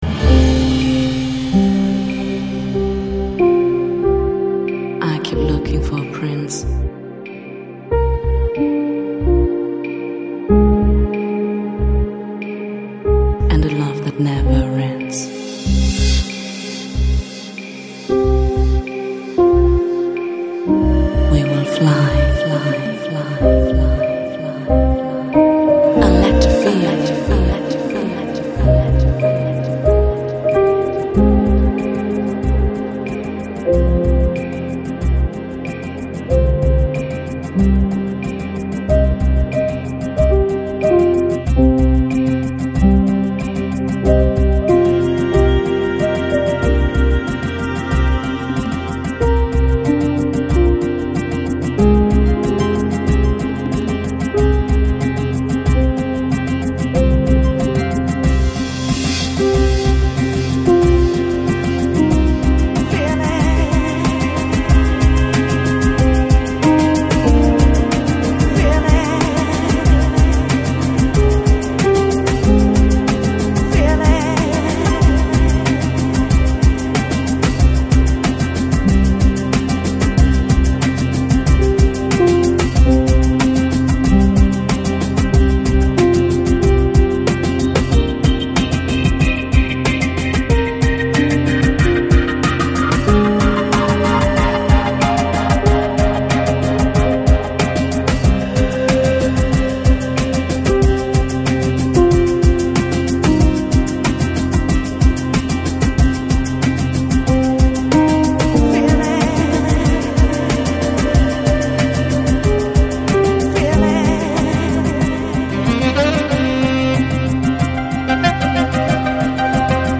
Lounge, Chillout, Easy Listening